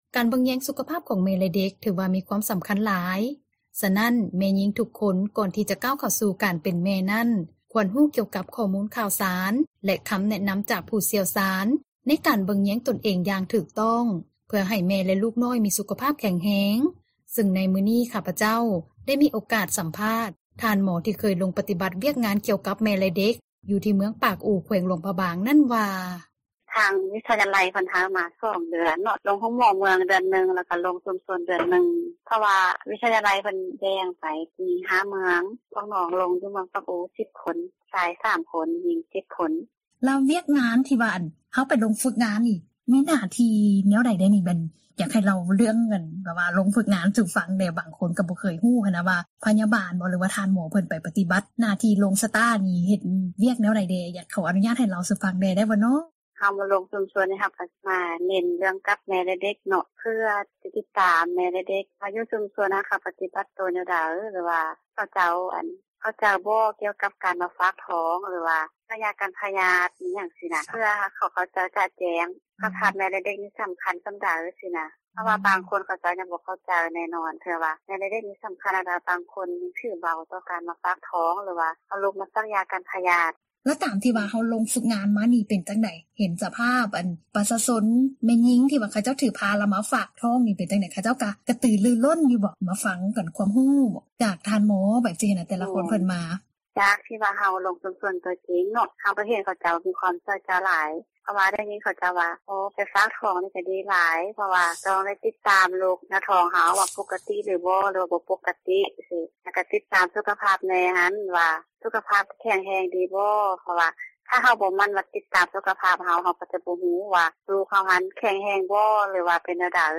ສັມພາດຜູ້ຊ່ຽວຊານ ແມ່ແລະເດັກ